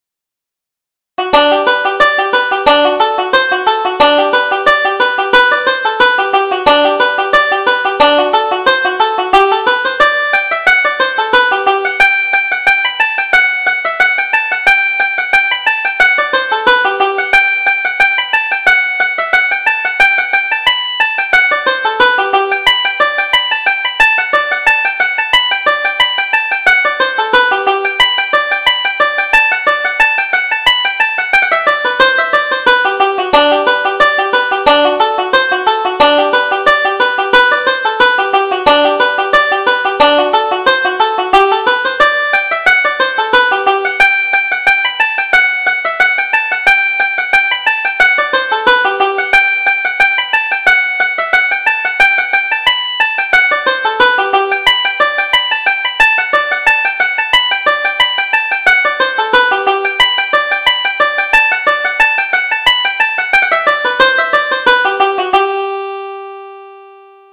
Reel: The Heather Breeze